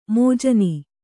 ♪ mōjani